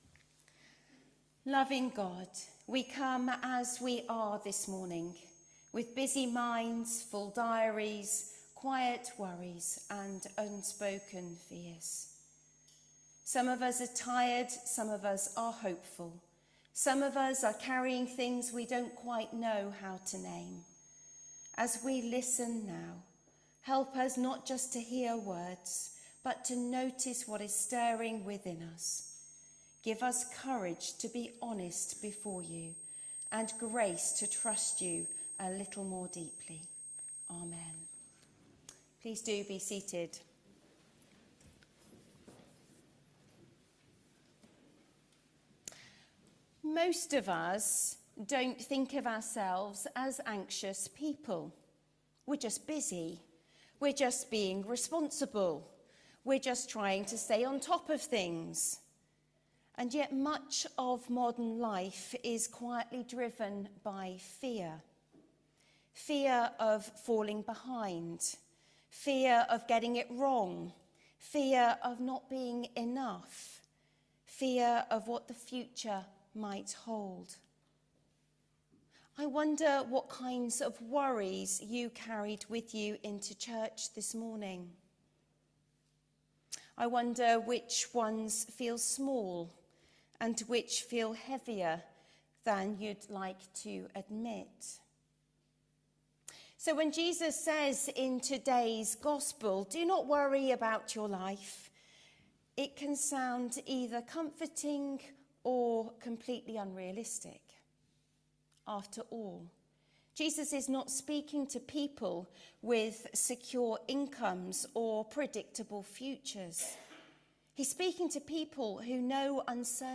HomeSermonsTrust opens us up